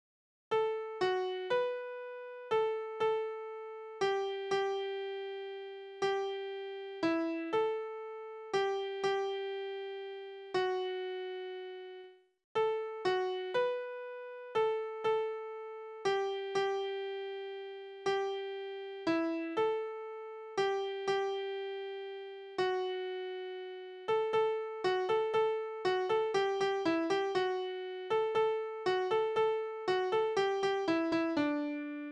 Kindertänze: Petersilie Suppenkraut
Tonart: D-Dur
Taktart: 3/4
Tonumfang: große Sexte